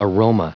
Prononciation du mot aroma en anglais (fichier audio)
Prononciation du mot : aroma